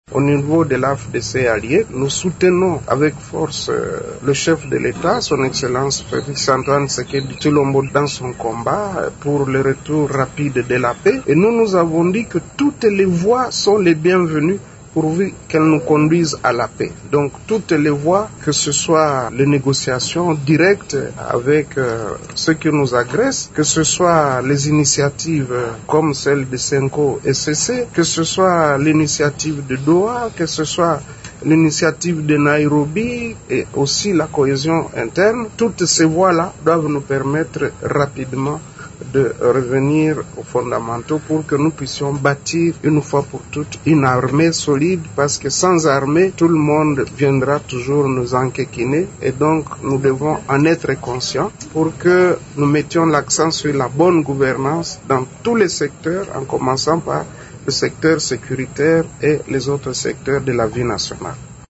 Bahati Lukwebo s’est exprimé à l'issue de son échange avec le conseiller spécial du chef de l'Etat en matière de sécurité dans le cadre des consultations politiques, en vue de la formation d’un gouvernement d’union nationale pour notamment mettre fin à la crise sécuritaire dans l’Est de la RDC.